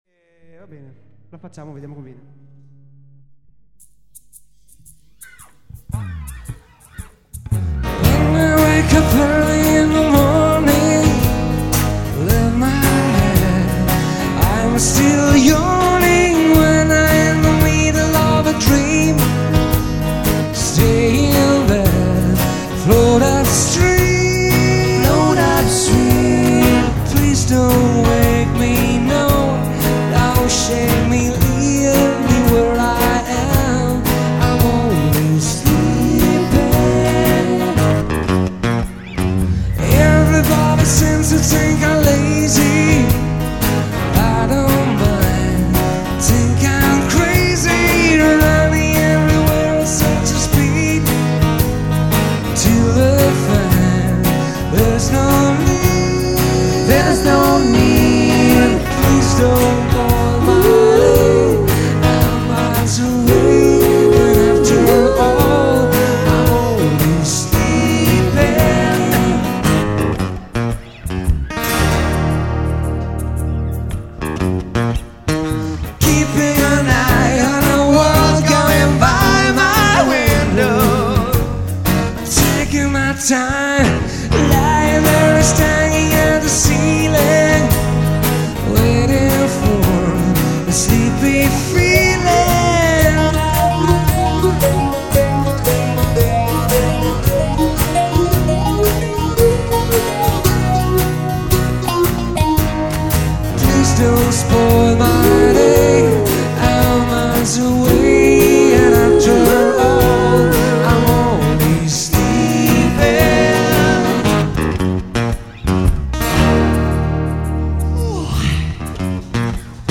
cover di vario genere riarrangiate in chiave acustica
Live @ Settimo Cielo, PR